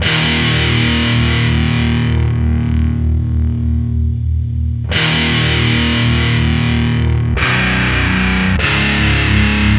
Rock Star - Power Guitar 06.wav